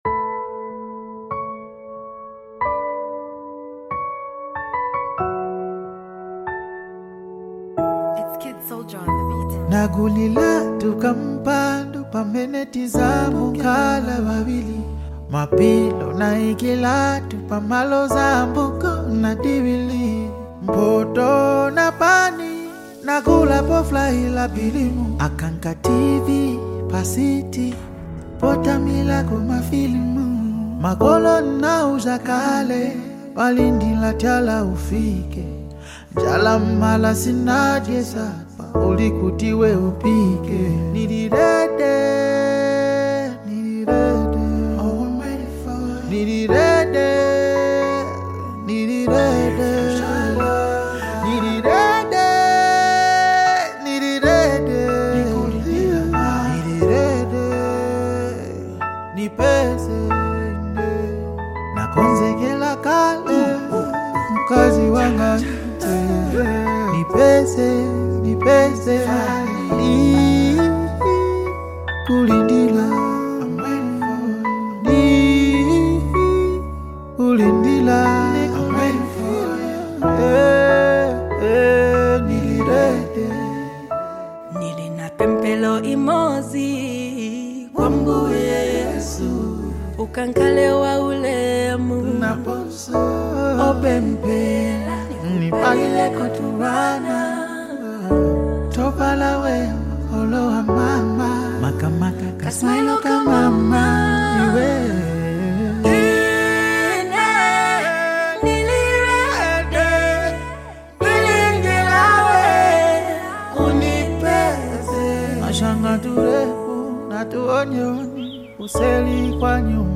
a smooth and emotionally layered song
signature soulful delivery
The mellow production